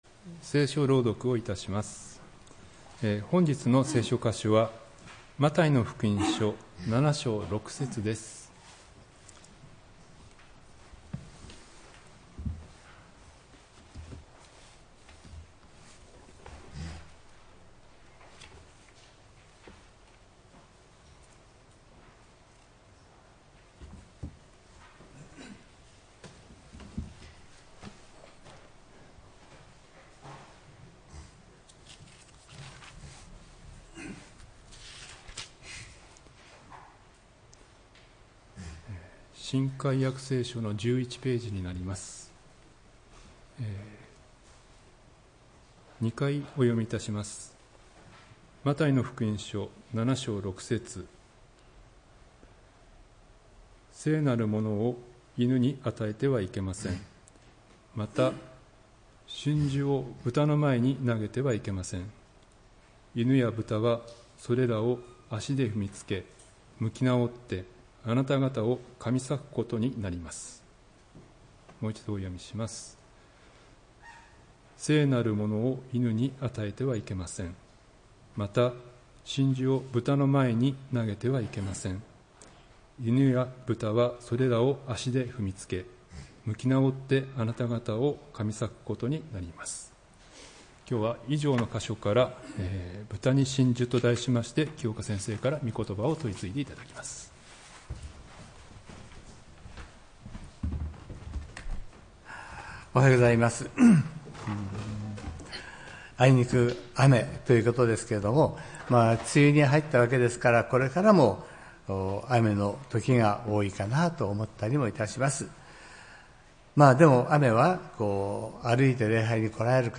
礼拝メッセージ「豚に真珠」(６月15日）